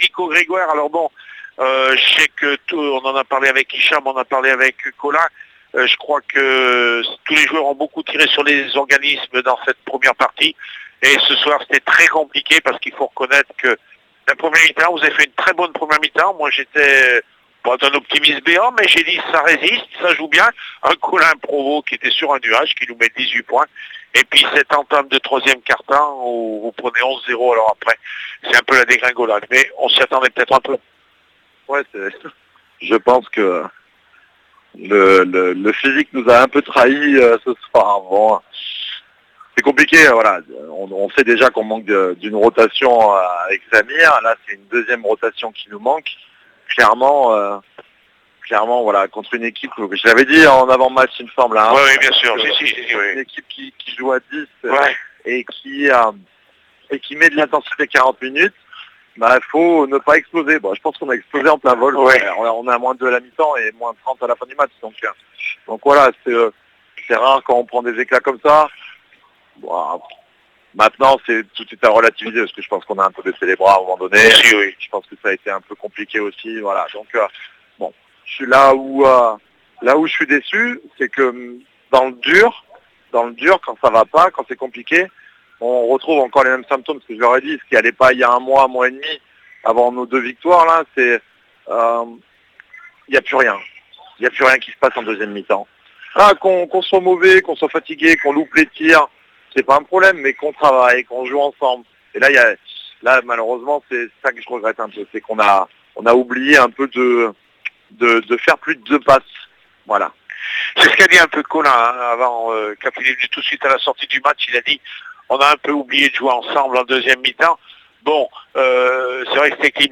Coupe du lyonnais basket 1/2 finale jl bourg 83-53 asmb le puy 17/12/16 après match